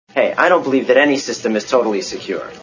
War Games Movie Sound Bites